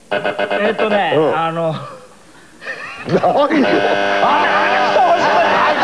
そのため、司会やパネリストの声がかぶっており、このままではＳＥとして使い回すことはできません。
時間切れ！ 127KB なかなか答えなかったときに出されます。「ブブブブブ、・・・・・・、ブー。」